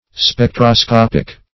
Spectroscopic \Spec`tro*scop"ic\, Spectroscopical